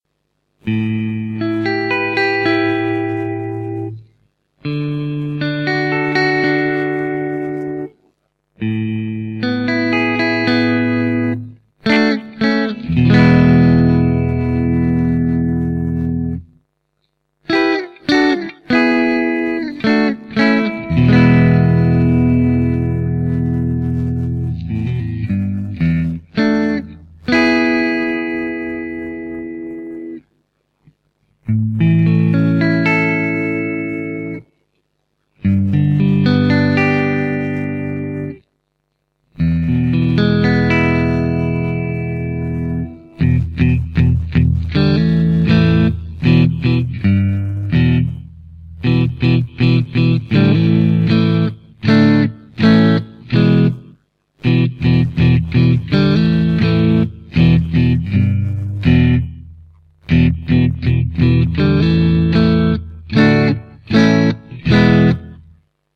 minotaur alnico 5 stratocaster pickup with P90 warmth
Give your Strat a major boost with an alternative approach to strat pickups, the Minotaur offers a blend of traditional Strat and P-90 sounds. Minotaurs work with any level of gain, from pristine clean to the dirtiest filth but they really excel in the middle range from just starting to break up to heavy crunch tones.
The bridge pickup is slightly more P-90 than strat, the middle is about 50-50 and the neck is slightly more strat than P-90.